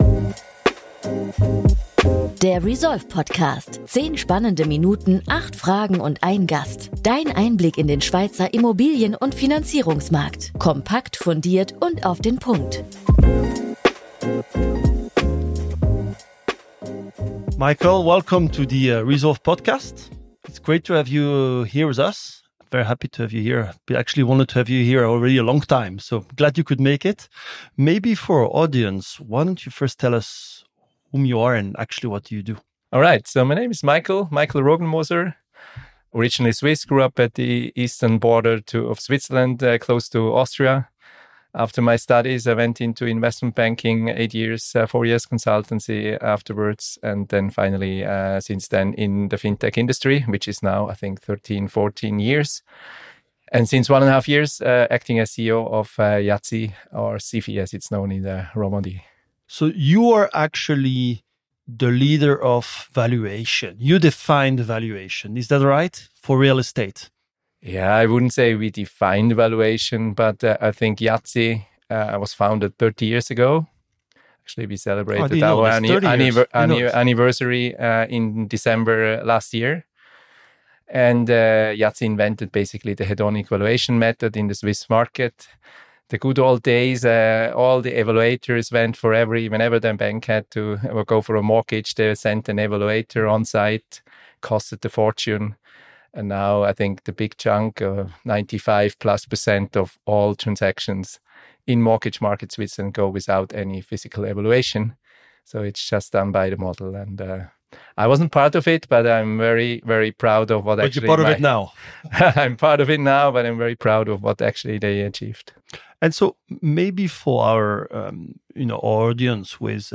The conversation explores the methodology behind IAZI's innovative valuation models, including their reliance on AI and transaction data from financial institutions.